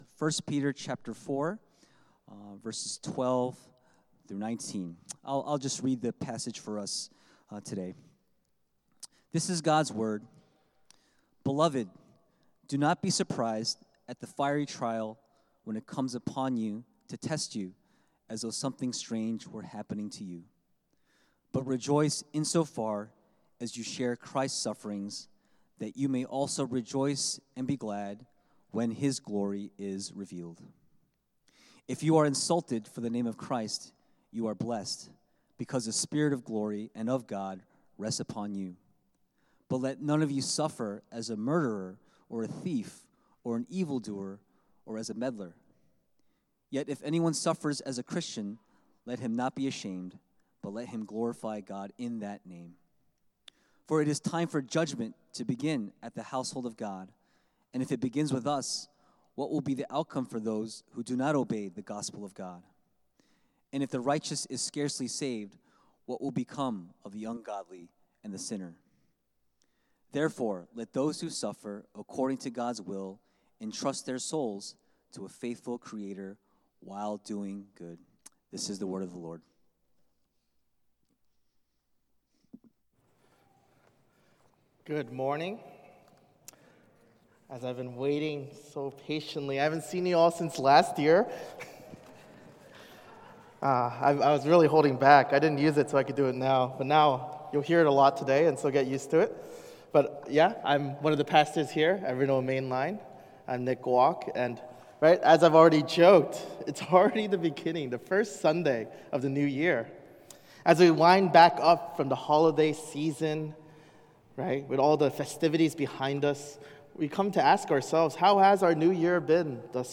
Weekly Sermons from Renewal Main Line